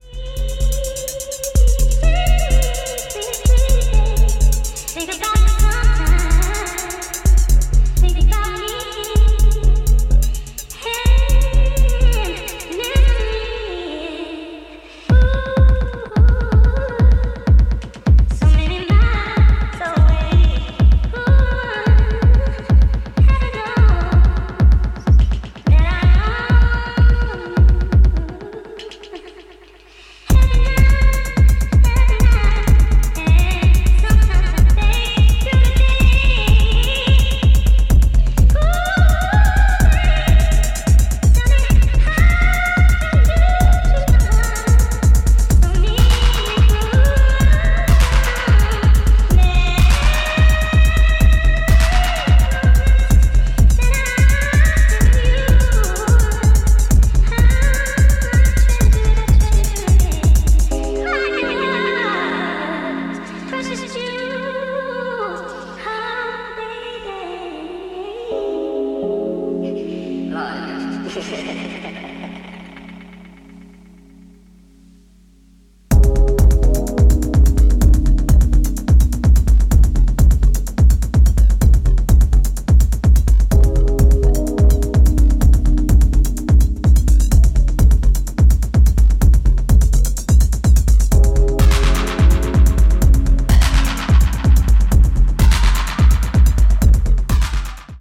House Techno